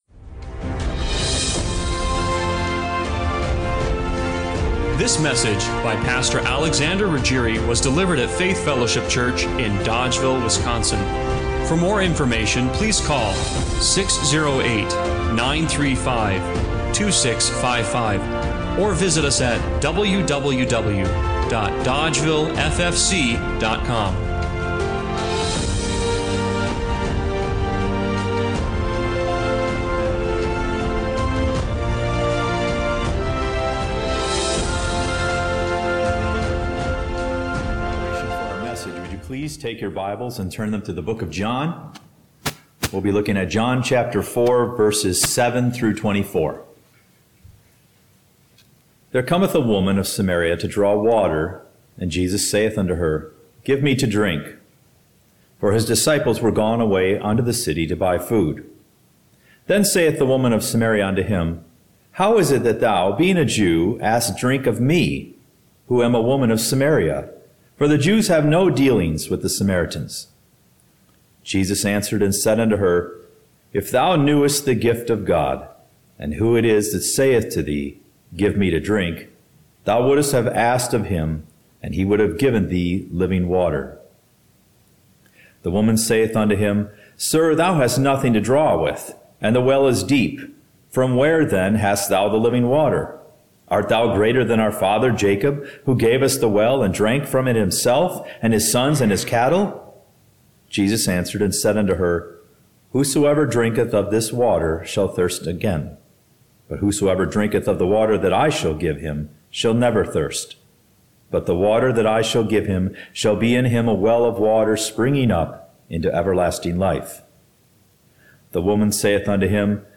John 4:7-24 Service Type: Sunday Morning Worship Did you know that there is a kind of worship which is of little interest to God?